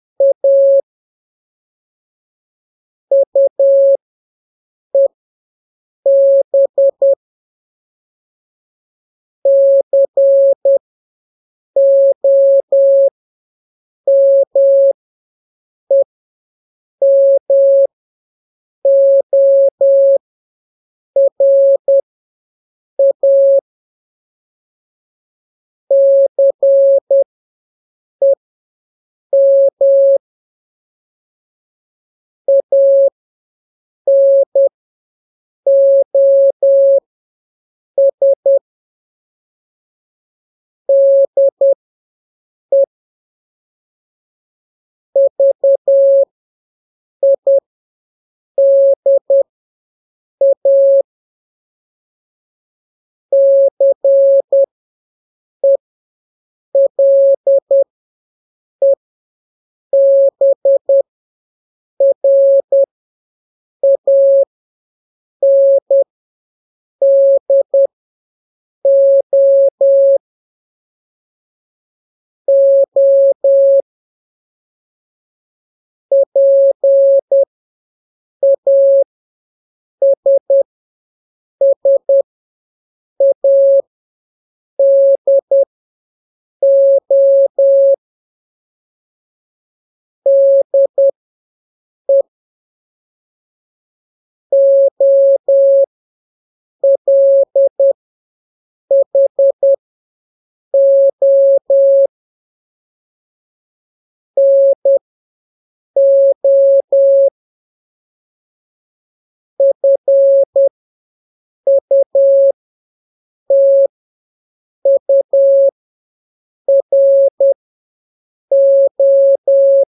Aqui está o áudio do Desafio do Rádio #12 - Telegrafia.�
mensagem_telegrafia.mp3